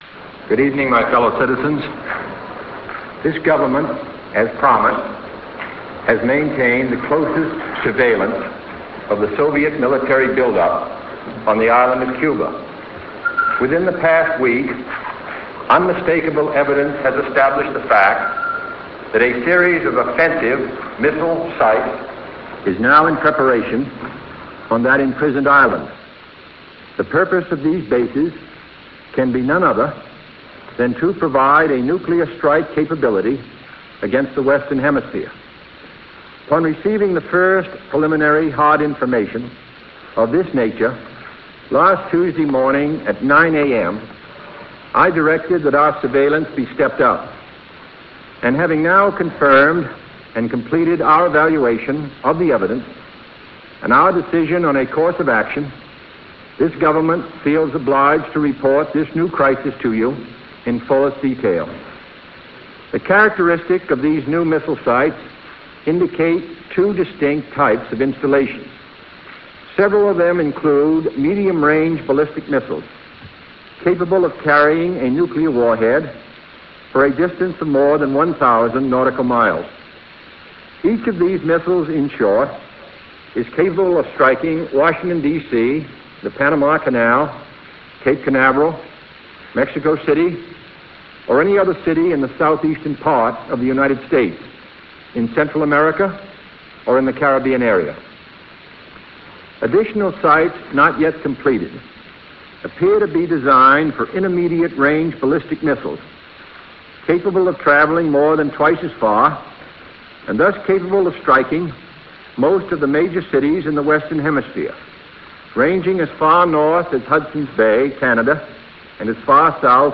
President John F. Kennedy's radio report given to the American people on October 22, 1962, regarding the Soviet arms buildup in Cuba.
10_jfk_quarantine_speech.rm